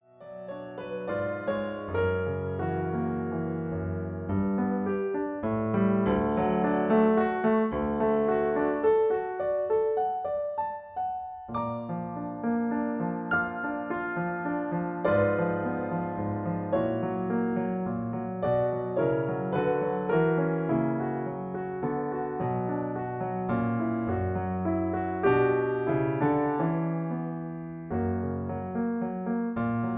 In B. Piano accompaniment